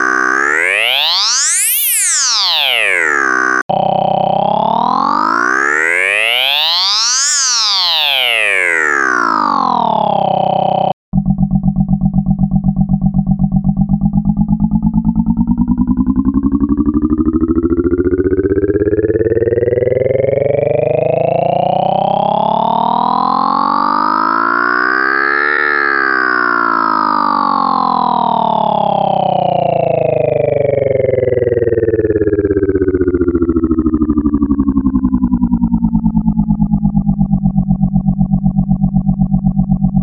Category 🎵 Music